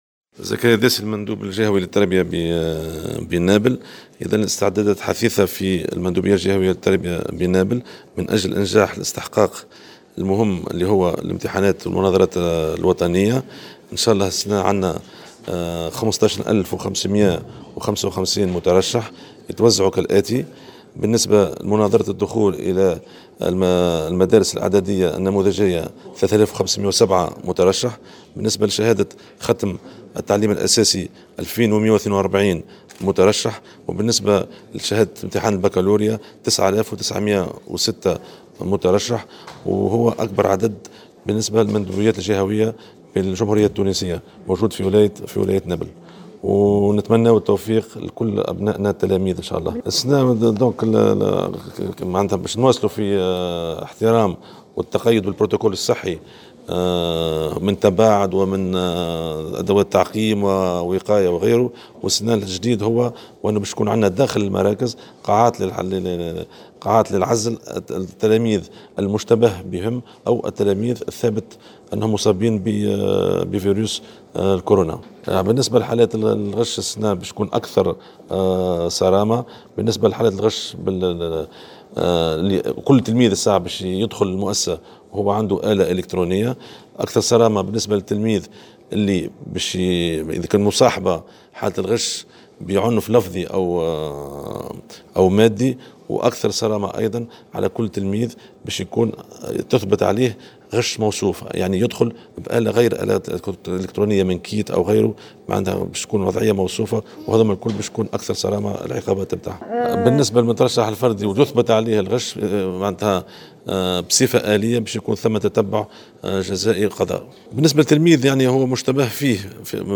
وأشار المندوب الجهوي، في تصريح لمراسلة الجوهرة أف أم، إلى أن عدد مراكز الإختبارات الكتابية بلغ 27 مركزا.